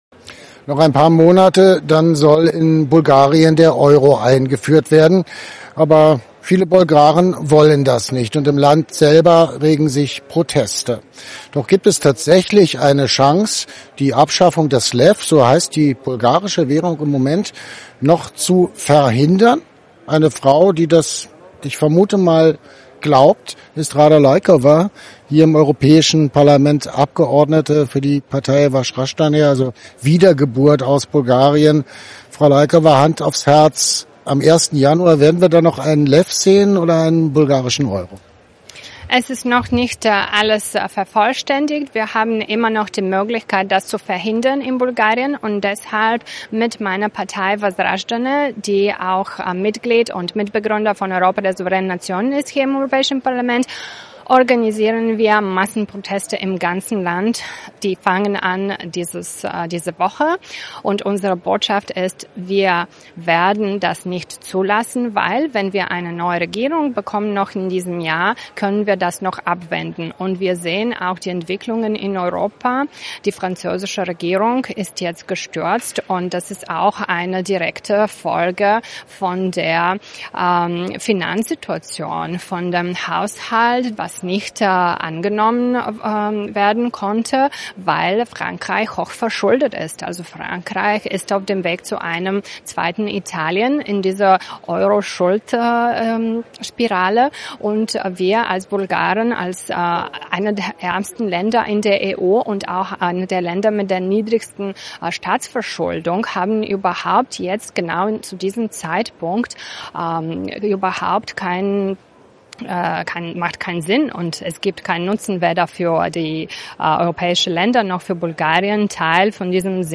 Beschreibung vor 4 Monaten Die bulgarische EU-Abgeordnete Rada Laykova (Wasraschdane) warnt im AUF1-Interview vor den Folgen der geplanten Euro-Einführung in ihrem Land. Hinter dem Schritt stehe keine wirtschaftliche Notwendigkeit, sondern eine politische Agenda aus Brüssel – mit dem Ziel, die Mitgliedsstaaten finanziell enger zu binden und kriegswirtschaftlich auszurichten.